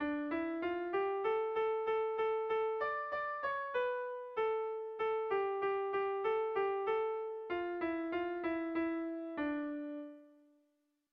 Dantzakoa
Trikiti doinua, Tiriki tiki tauki edota Baratzeko pikuak bezalatsu.
AB